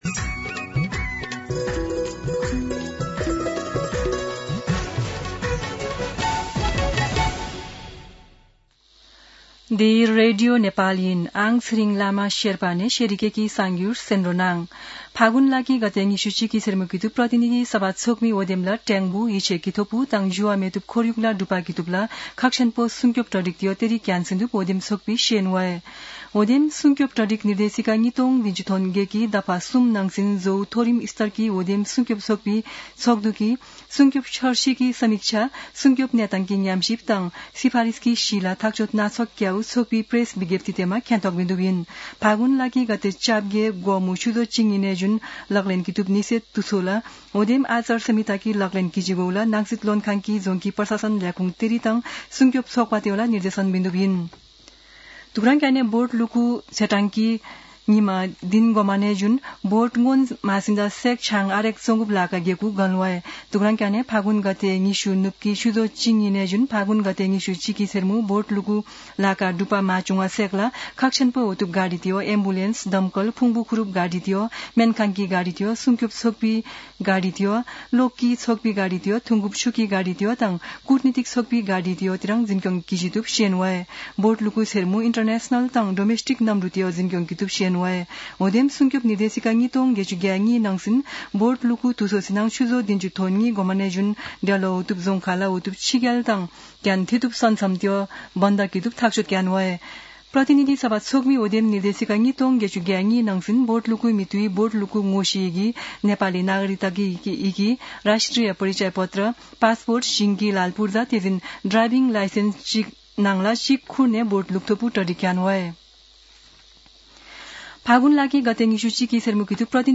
शेर्पा भाषाको समाचार : १४ फागुन , २०८२
Sherpa-News-11-14.mp3